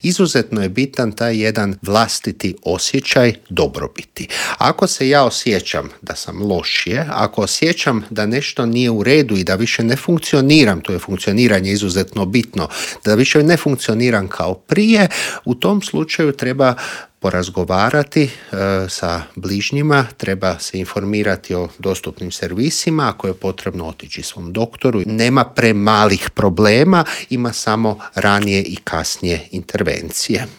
ZAGREB - Najdepresivniji je dan u godini pa smo u studiju Media servisa razgovarali o mentalnom zdravlju.